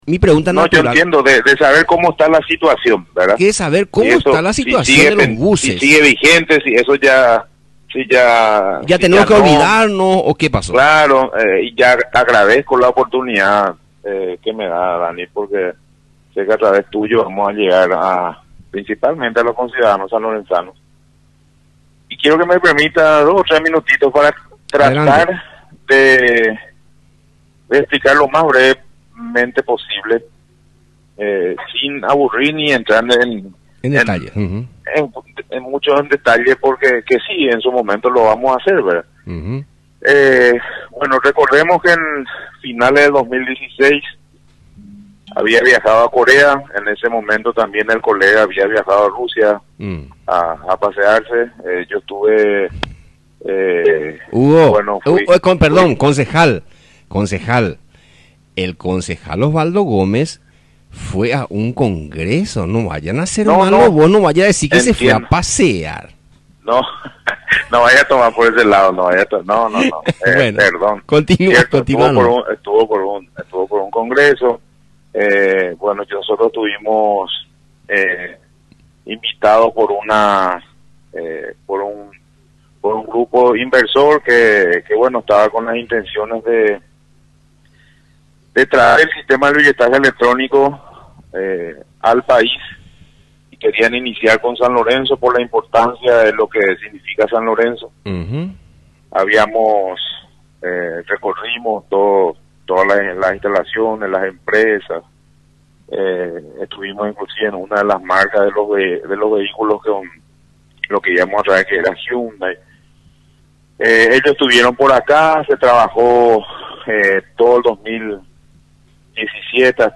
Lo que dijo el edil Hugo Lezcano